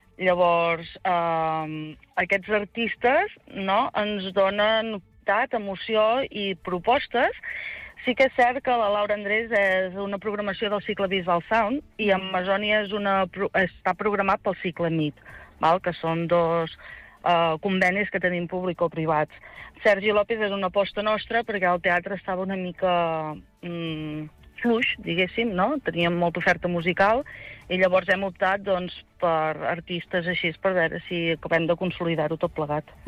En una entrevista al Supermatí, la regidora de Cultura de la Bisbal d’Empordà, Eva Rovira, ha presentat la programació del Teatre Mundial per a l’hivern 2026, que combina espectacles amb artistes reconeguts, teatre familiar i projectes de proximitat.